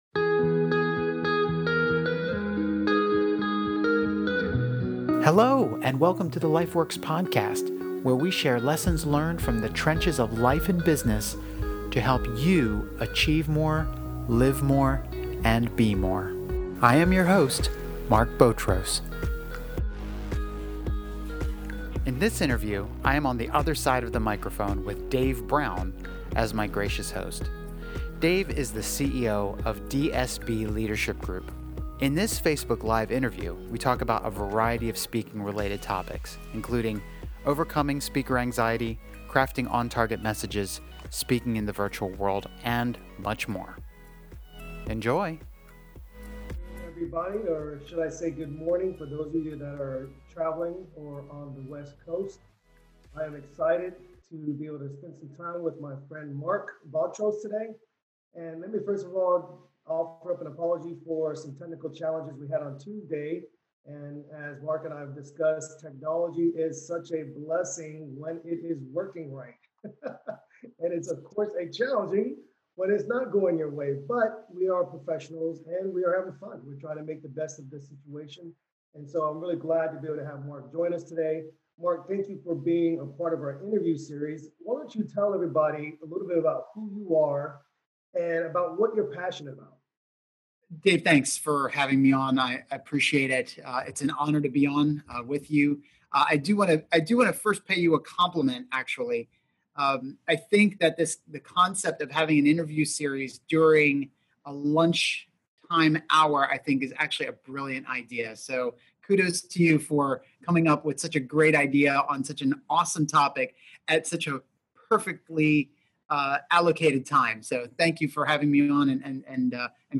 Episode 033: Overcoming Speaker Anxiety (A Facebook LIVE Interview) (31:28)